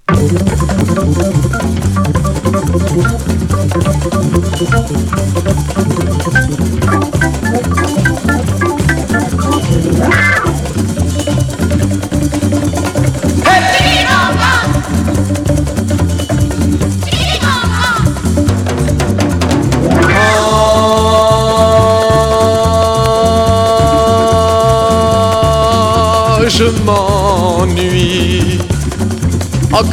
Chanteur 60's Sixième 45t retour à l'accueil